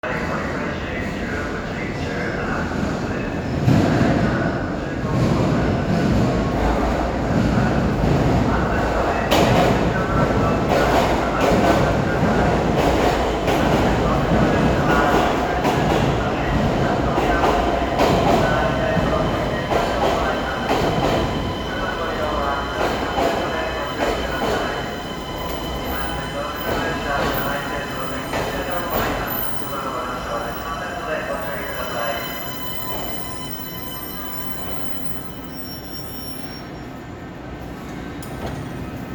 ・AE形走行音(外から)
到着（京成上野にて）
京成ではおなじみの東洋IGBTですが、3000形や3100形のそれとはモーター音が異なり、近い音を出す車両を挙げるなら京阪3000系等に類似していると言えそうです。